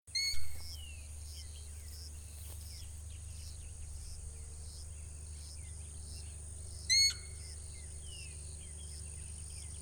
Saracuruçu (Aramides ypecaha)
Nome em Inglês: Giant Wood Rail
Localidade ou área protegida: Colonia Carlos Pellegrini
Condição: Selvagem
Certeza: Observado, Gravado Vocal